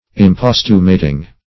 imposthumating.mp3